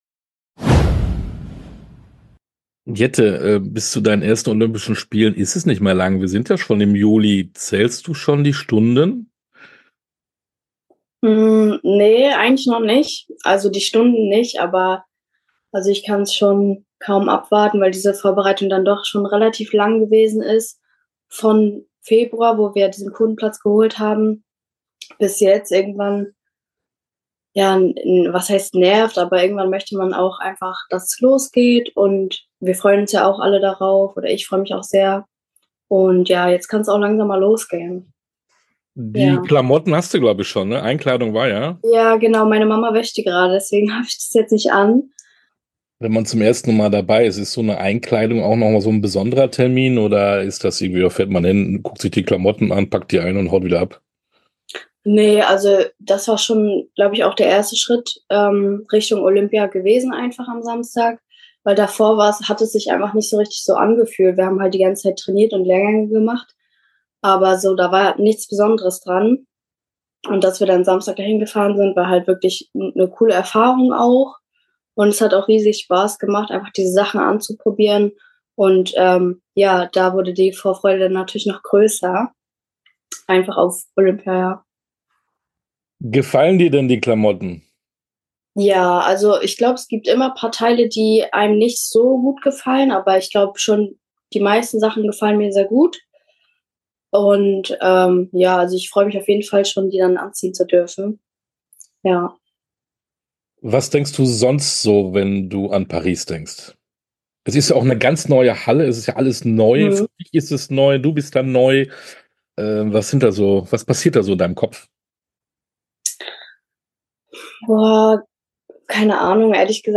Interviews in voller Länge Podcast